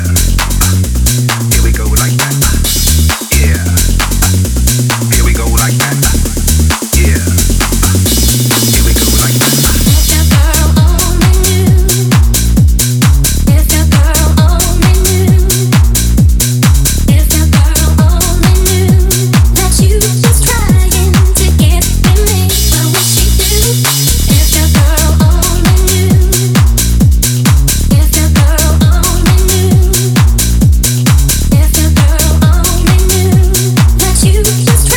Жанр: Танцевальные / Хаус
Dance, House